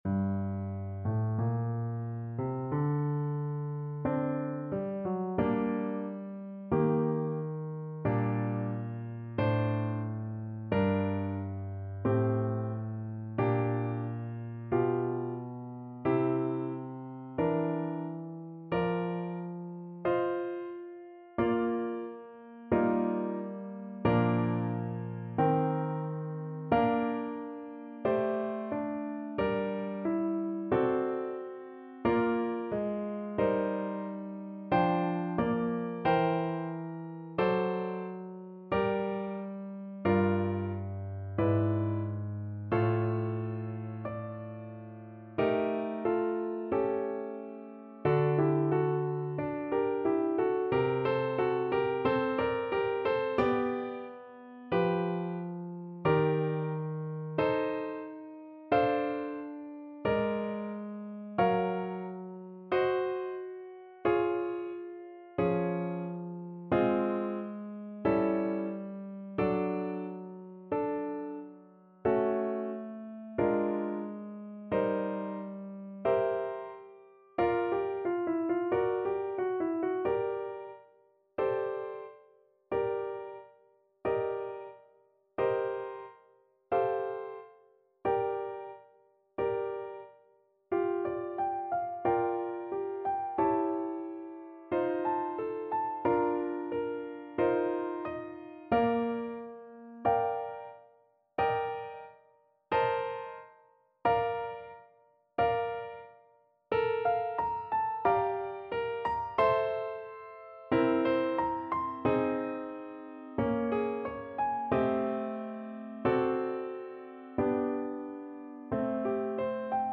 Play (or use space bar on your keyboard) Pause Music Playalong - Piano Accompaniment Playalong Band Accompaniment not yet available reset tempo print settings full screen
G minor (Sounding Pitch) A minor (Clarinet in Bb) (View more G minor Music for Clarinet )
Adagio, molto tranquillo (=60) =45
Classical (View more Classical Clarinet Music)